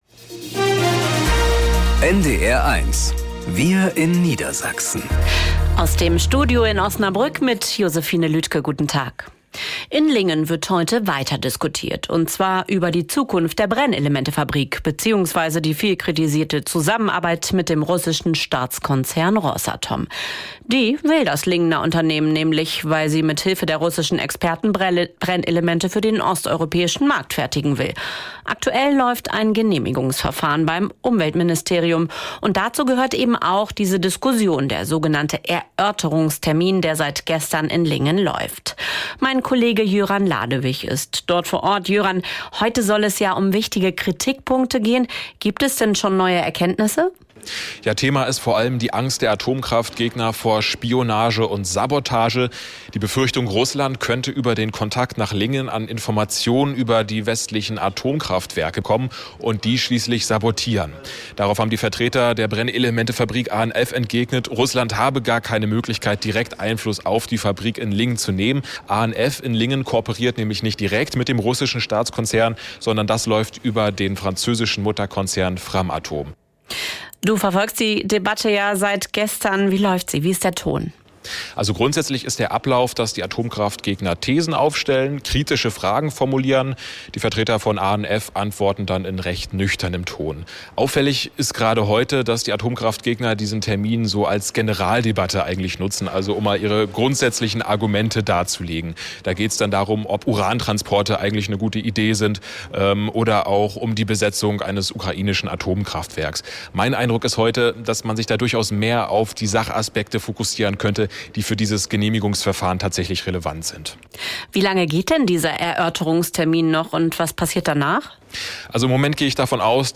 … continue reading 5 episode # Tägliche Nachrichten # Nachrichten # NDR 1 Niedersachsen